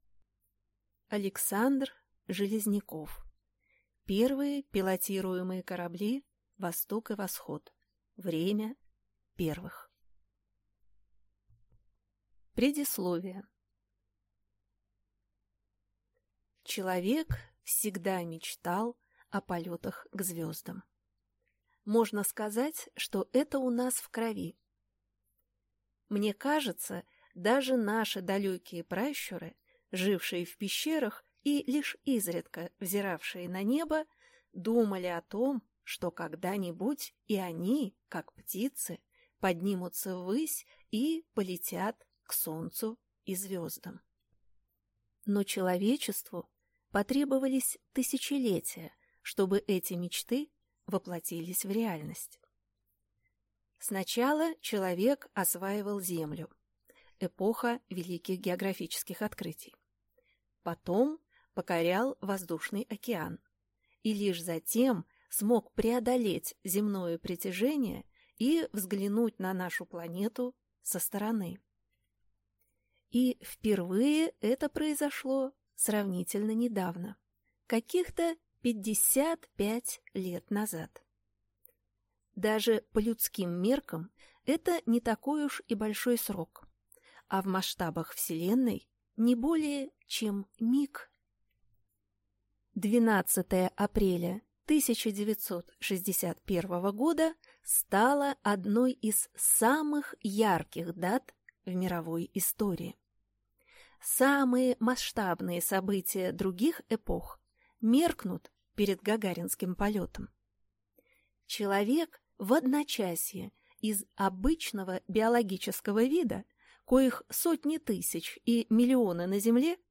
Аудиокнига Первые пилотируемые корабли «Восток» и «Восход». Время первых | Библиотека аудиокниг